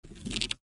arrowdraw.3b0039d1101b6bc56e29.mp3